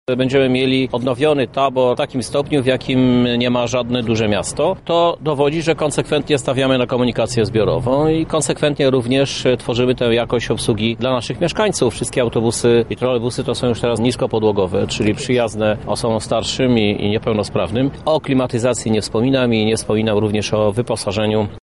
Już dawno przekroczyliśmy wymagany próg, by móc być nazywanym miastem o zrównoważonym transporcie – mówi Prezydent Miasta, Krzysztof Żuk: